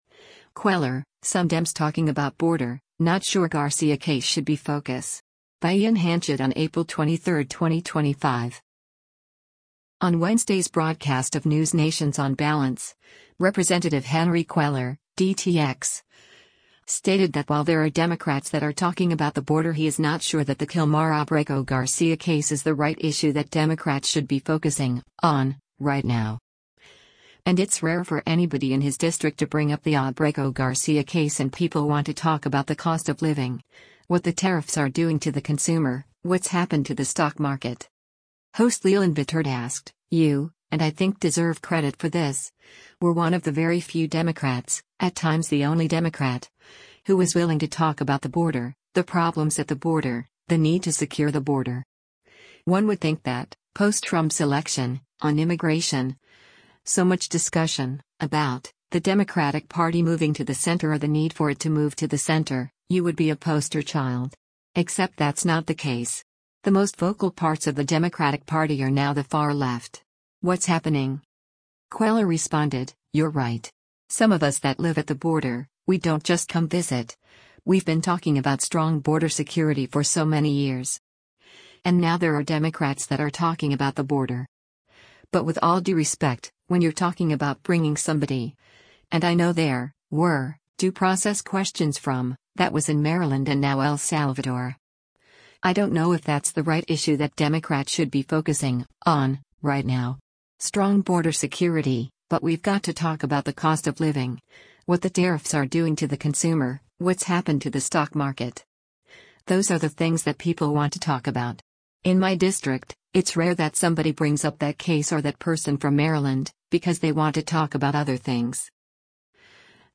On Wednesday’s broadcast of NewsNation’s “On Balance,” Rep. Henry Cuellar (D-TX) stated that while “there are Democrats that are talking about the border” he’s not sure that the Kilmar Abrego Garcia case is “the right issue that Democrats should be focusing [on] right now.”